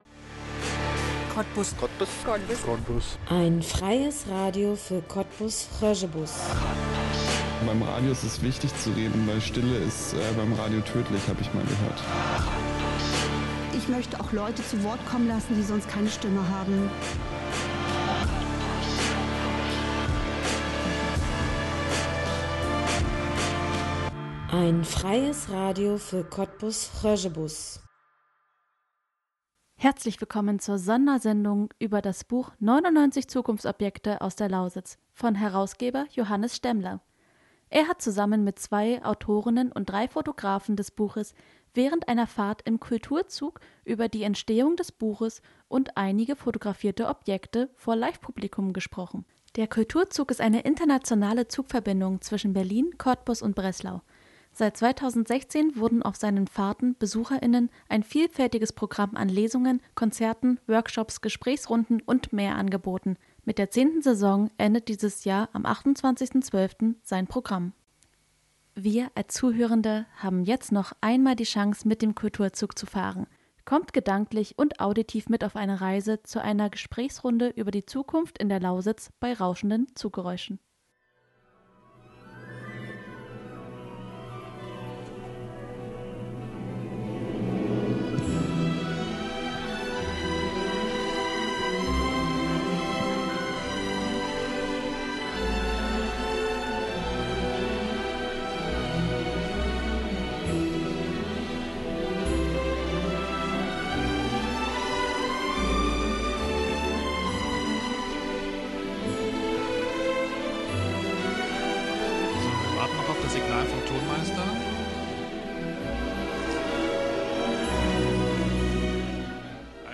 Es geht um die Entstehung des Buches und einige fotografierte Objekte. Kommt gedanklich und auditiv mit auf die Reise zu einer Gesprächsrunde über die Zukunft in der Lausitz bei rauschenden Zuggeräuschen.